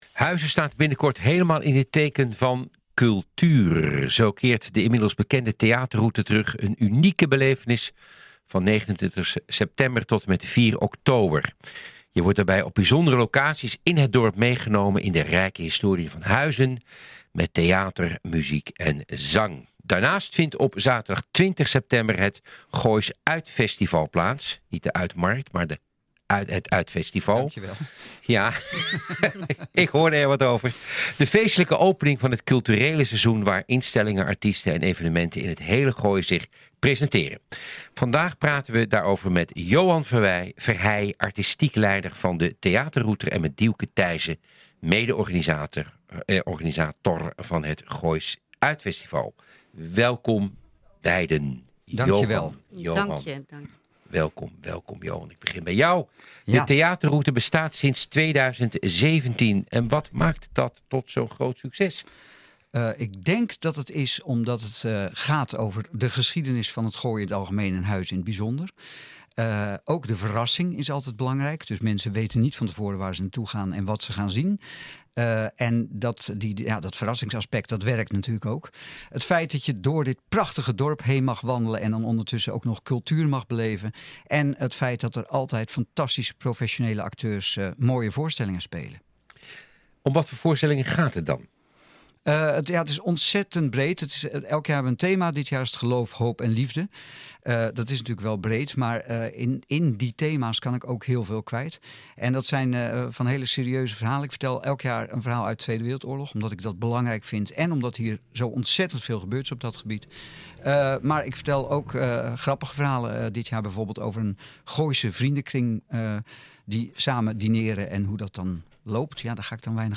Wij brengen nieuws en achtergronden op onze website, radio en televisie.